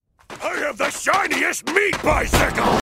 Play, download and share meat bike original sound button!!!!
borderlands-2-krieg-a-meat-bicycle-built-for-two-mp3cut_RN7KnRN.mp3